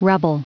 Prononciation du mot rubble en anglais (fichier audio)
Prononciation du mot : rubble